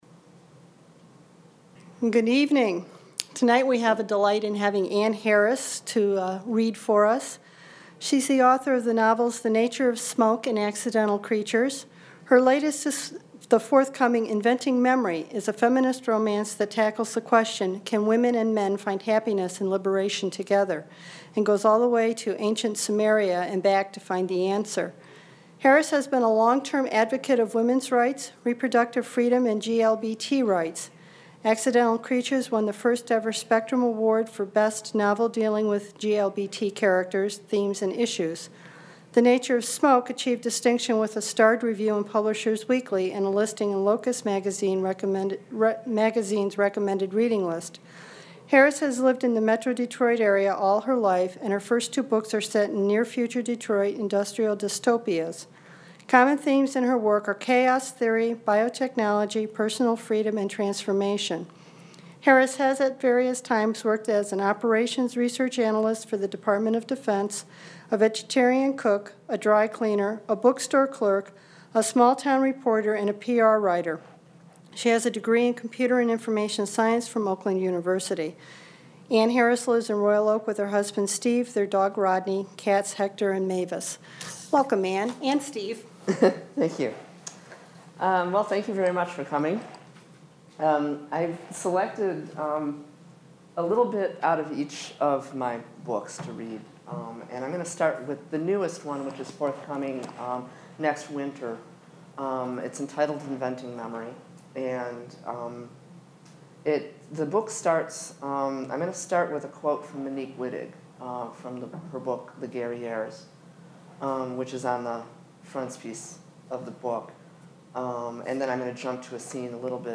Held in the Main Library.